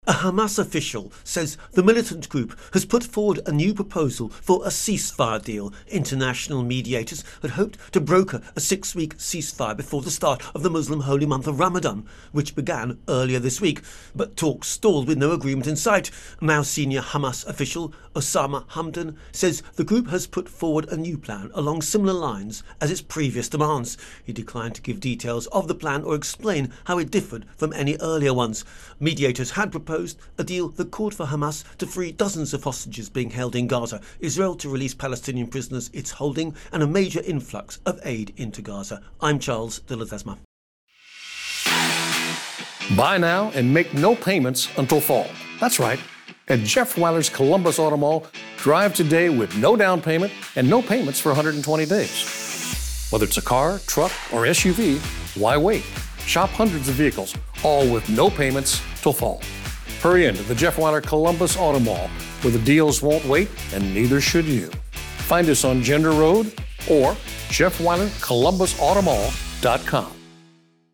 reports on a new Hamas statement during Ramadan.